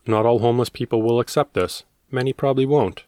I’m also using a budget condenser microphone, but these clicks weren’t showing up in earlier recordings, or even recordings I’ve done after this chapter, so I have to assume the mic isn’t the problem.
There are 3 consecutive clicks that start right at the 1 second mark of that clip. They’re not mouth clicks and there is no parts missing from the wave file, it looks smooth and good.
Got it. 1.1, 1.6, and 2.2
There is a lot more rough, trashy “grass” between 2.17 and 2.2.
I talk into a closet with clothes hanging everywhere and the laptop is outside the closet door, with a 3 foot usb cable.